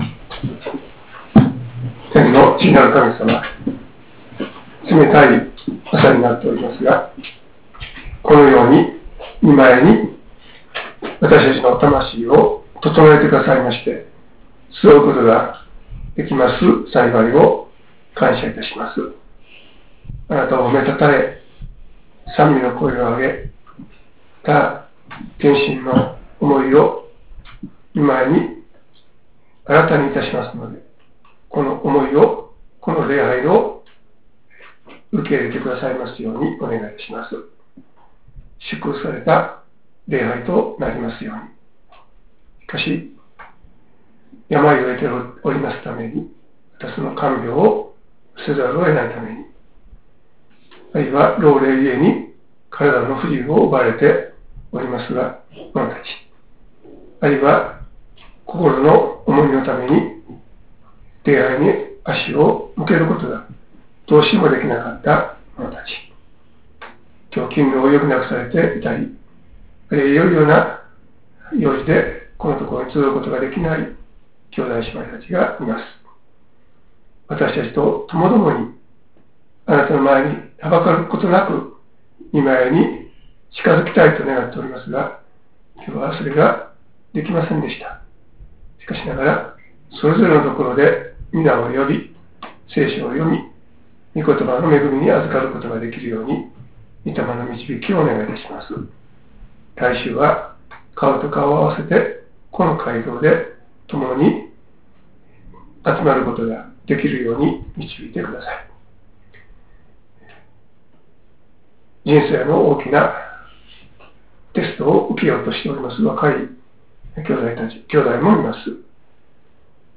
説教